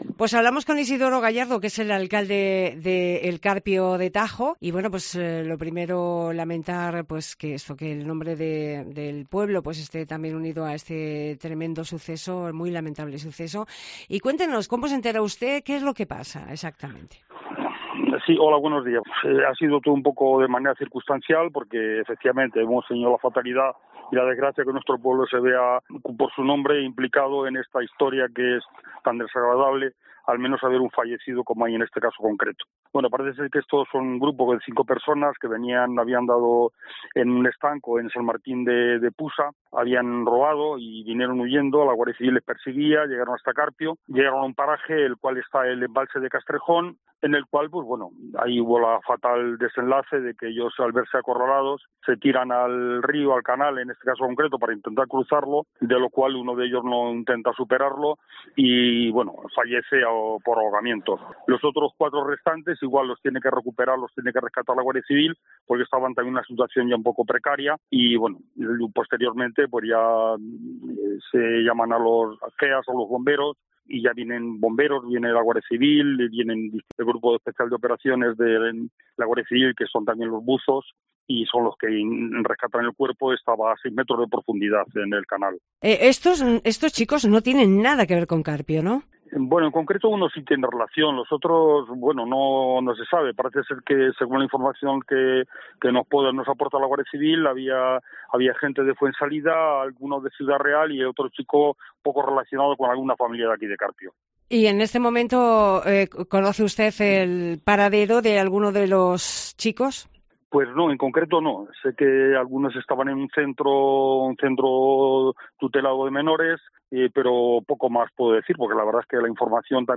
Isidoro Gallardo, alcalde de Carpio de Tajo, en COPE Toledo